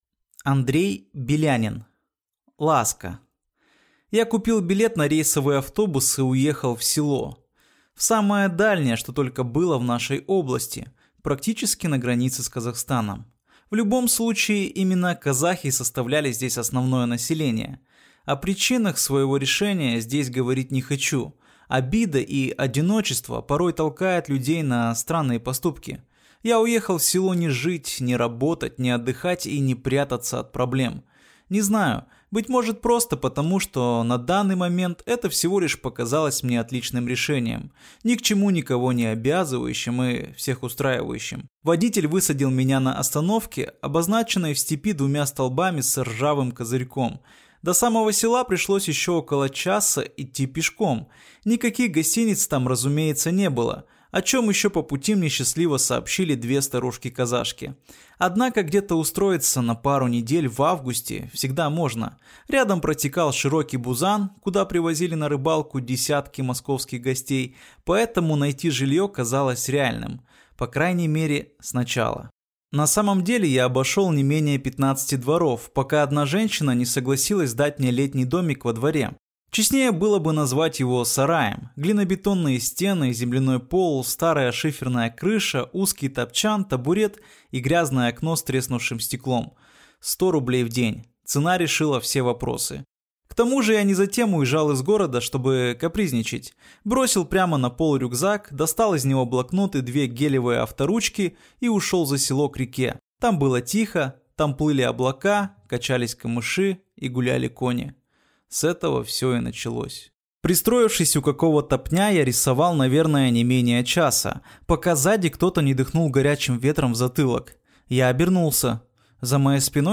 Аудиокнига Ласка | Библиотека аудиокниг